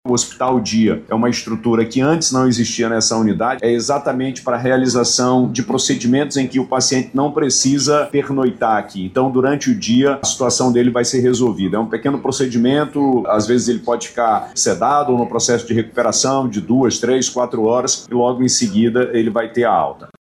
Durante a entrega, o chefe do Executivo Estadual explicou como vão funcionar os atendimentos no Hospital Dia.